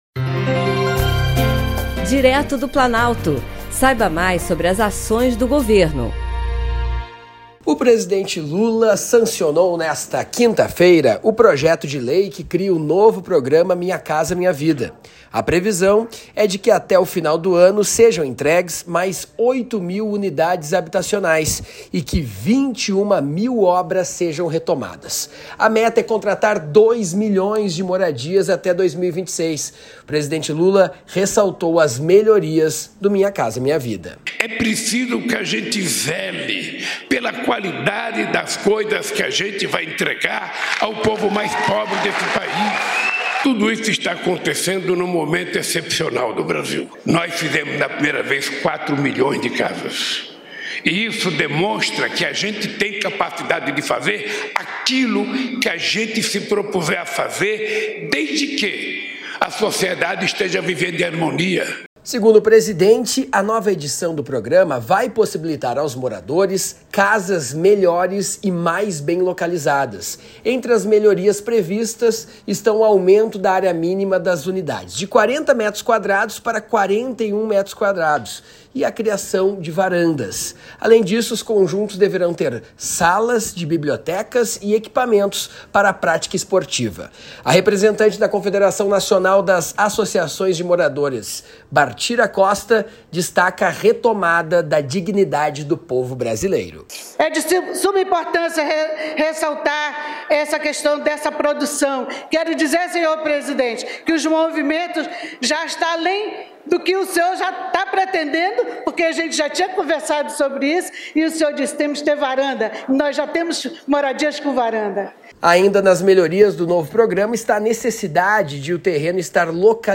Boletins de Rádio